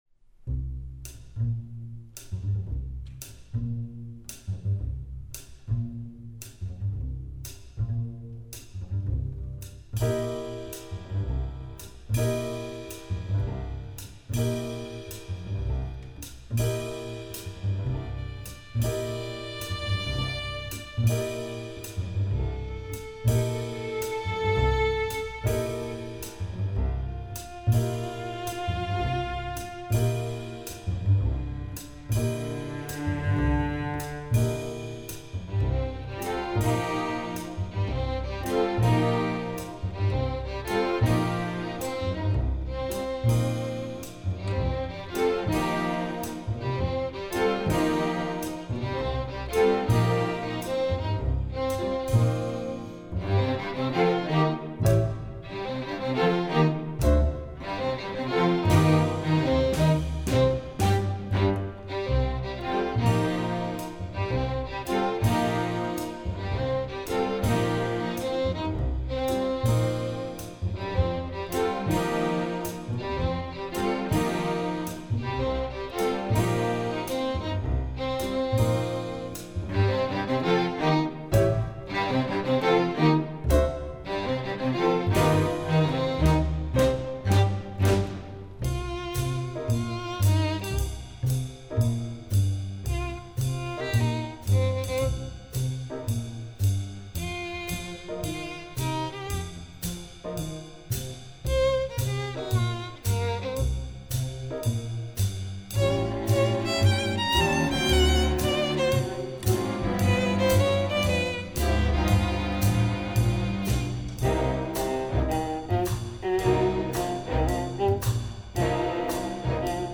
Piano accompaniment part:
Drums part: